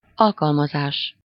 Ääntäminen
IPA: /ɑ̃.plwa/